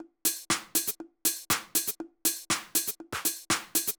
Drumloop 120bpm 03-B.wav